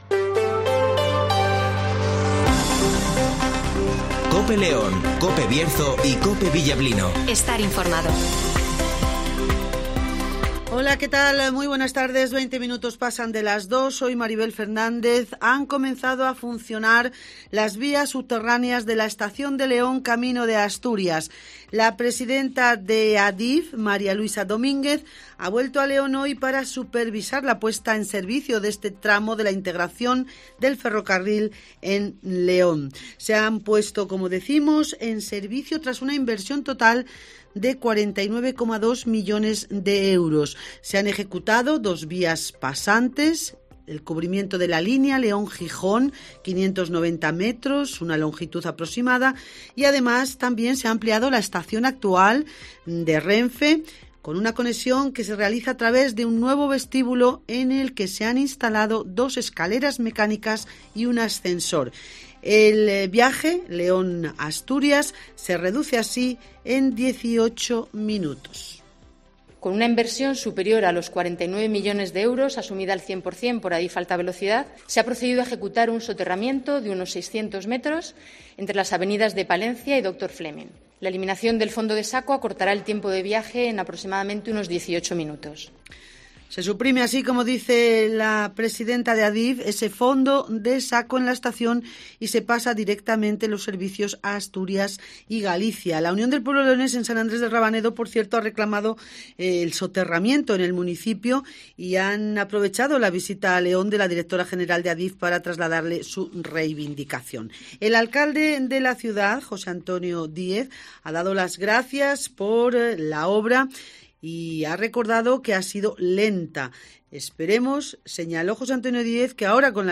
La presidenta de ADIF Mª Luisa Domínguez nos lo cuenta